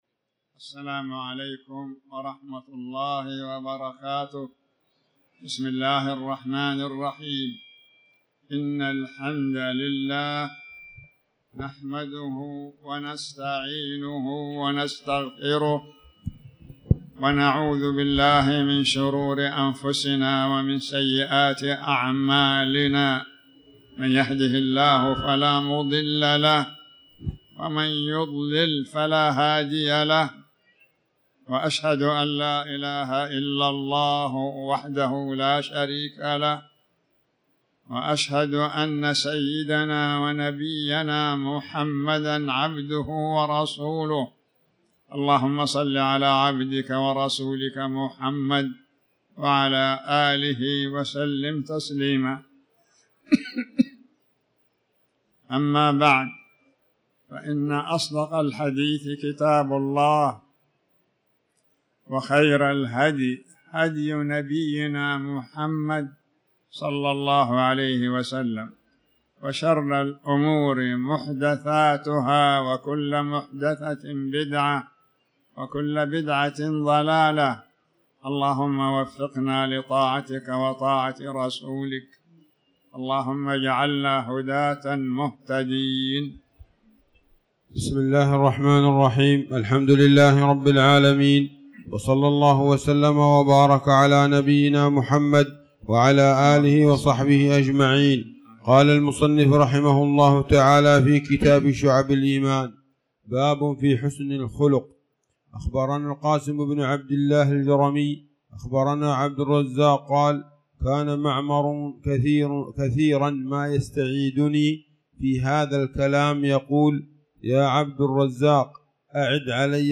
تاريخ النشر ٢٠ شوال ١٤٤٠ هـ المكان: المسجد الحرام الشيخ